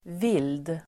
Uttal: [vil:d]